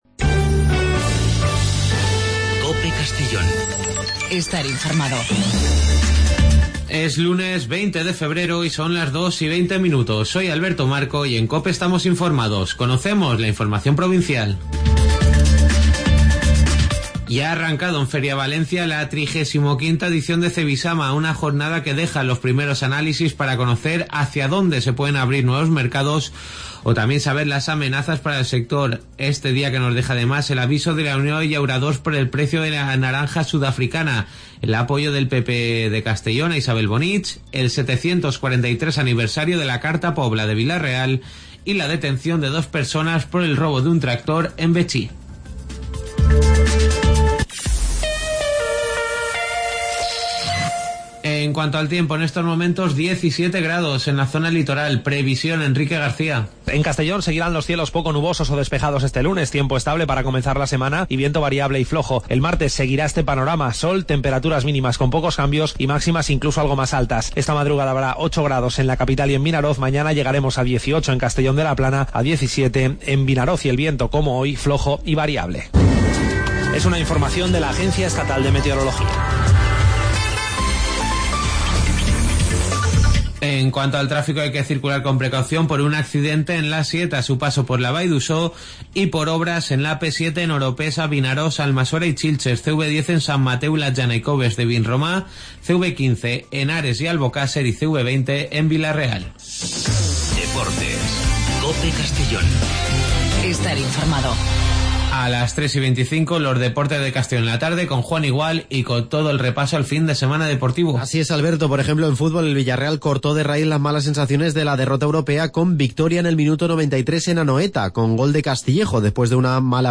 AUDIO: Las noticias del día de 14:20 a 14:30 en Informativo Mediodía COPE en Castellón.